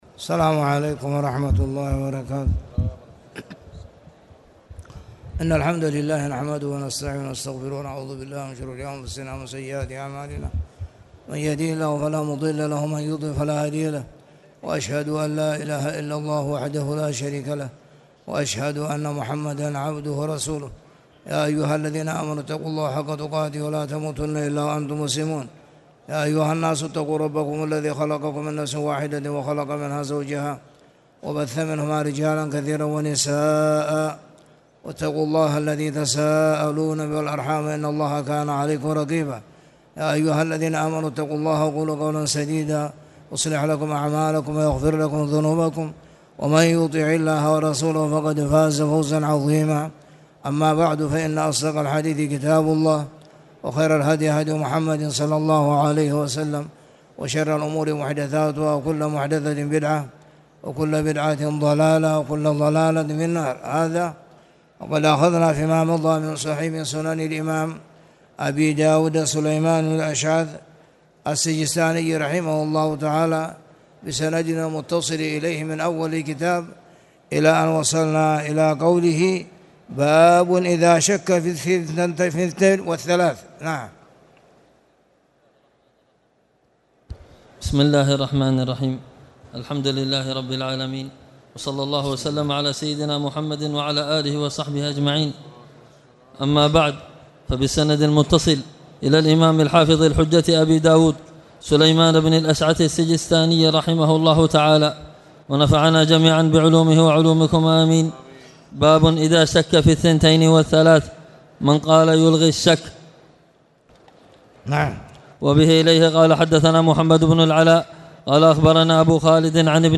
تاريخ النشر ٢٨ ربيع الأول ١٤٣٨ هـ المكان: المسجد الحرام الشيخ